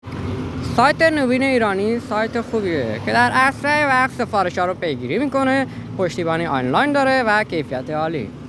نظرات مثبت مشتریان عزیزمون با صدا خودشون رضایت از سایت نوین ایرانی